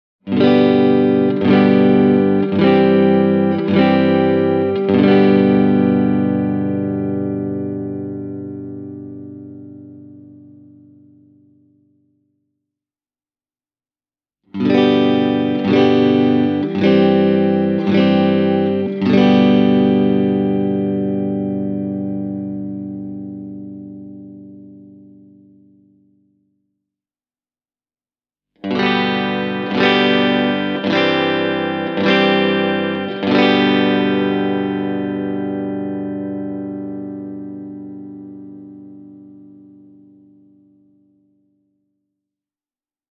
Testikitaraan oli asennettu kaksi P-90:tä, jonka ansiosta soundi menee samalla mehukkaan, mutta rouhean suuntaan, joka tuli tutuksi monesta The Who- tai Santana-klassikkobiisistä.
Tässä on esimerkki Arvon puhtaista perussoundeista (äänitetty suoraan Blackstar HT-1R -kombon linjalähdöstä):